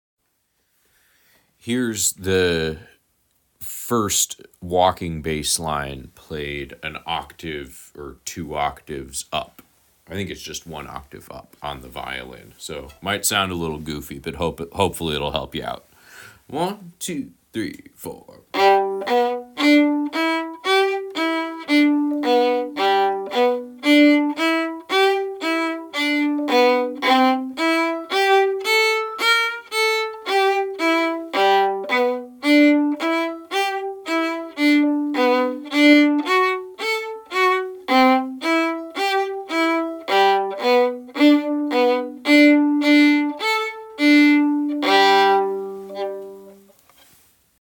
Milk Cow Blues walking bass no 1 (MP3)Download